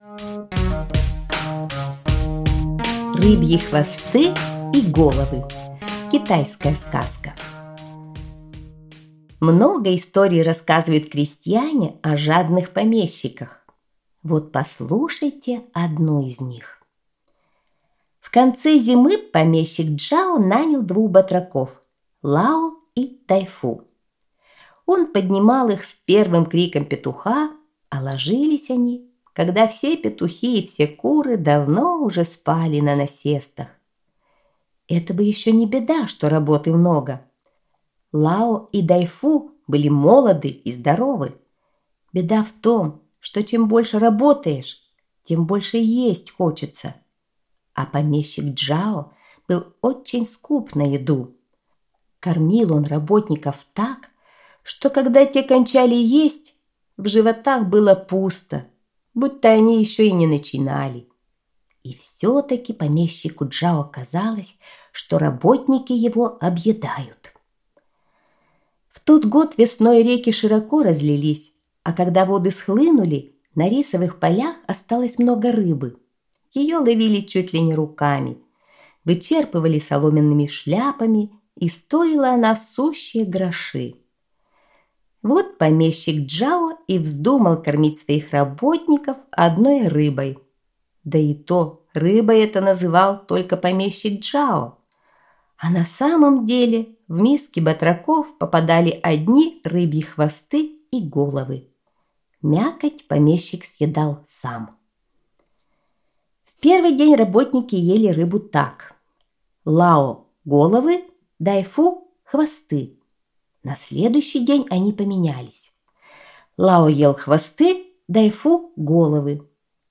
Рыбьи хвосты и головы – китайская аудиосказка